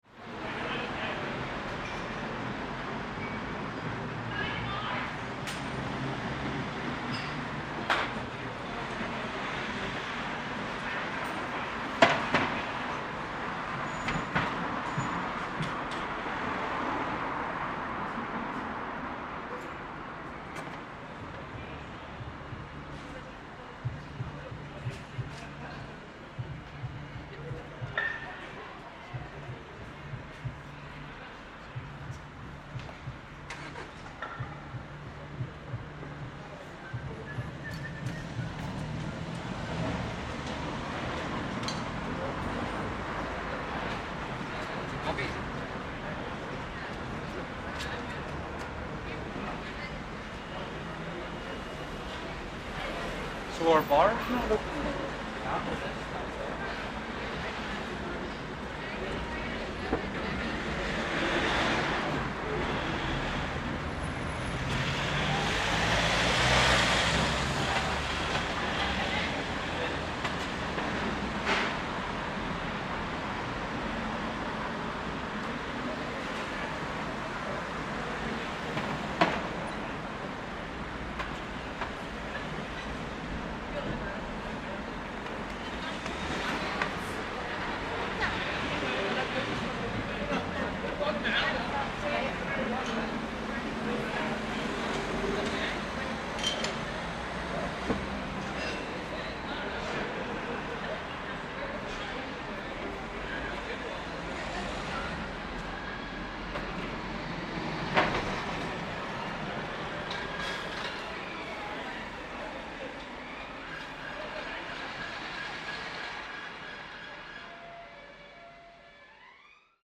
Recording in front of two bars which are closed/closing (Filthy’s – closed and The Points – closing), at The Points they were still people finishing their drinks on the outdoor terrace, staff picking up glassware, groups of people leaving the pub, chatter, passerby, vehicle tr...
Beginning of Lockdown 2 in Belfast.